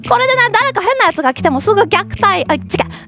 Worms speechbanks
Illgetyou.wav